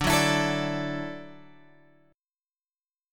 Dadd9 chord {10 9 x 9 10 10} chord